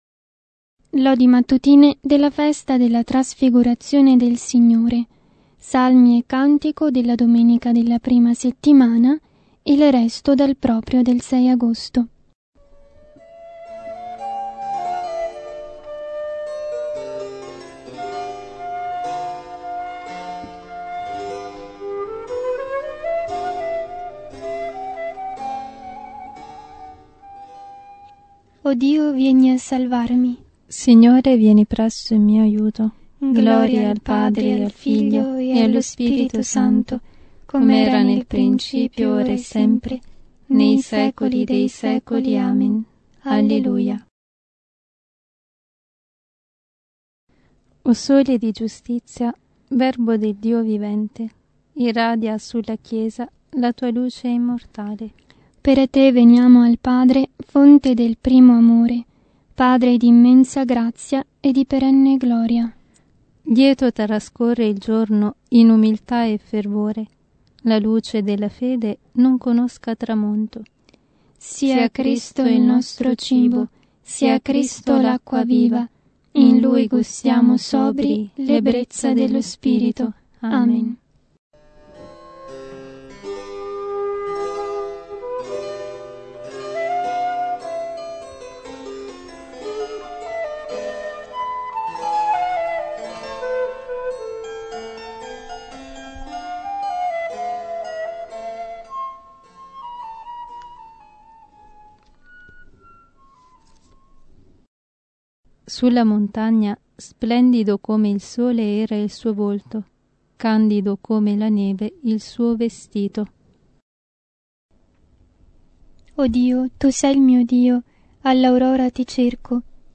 “Giovedì” by Lodi Mattutine. Genre: Festa della Trasfigurazione del Signore.
Lodi-Trasfigurazione.mp3